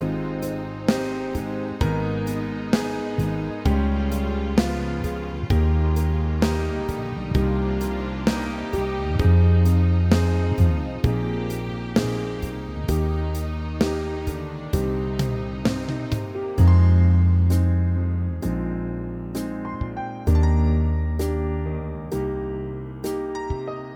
Minus Guitars Pop (1970s) 3:44 Buy £1.50